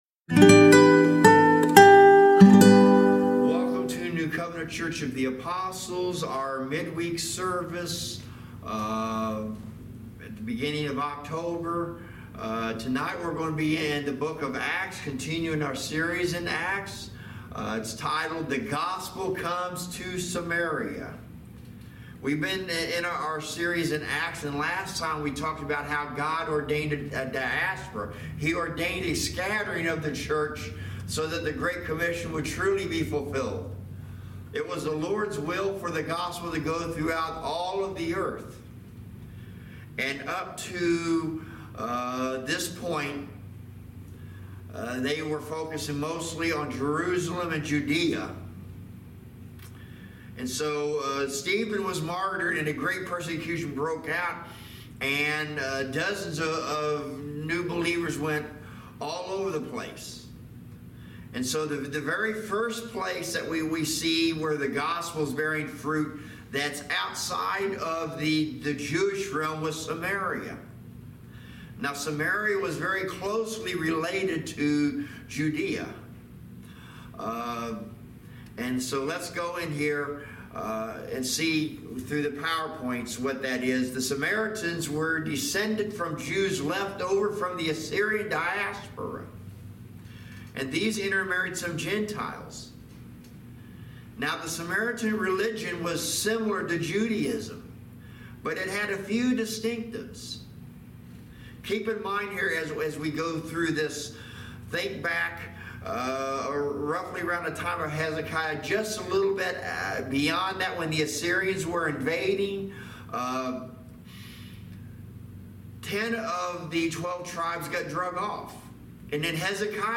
Service Type: Thirsty Thursday Midweek Teaching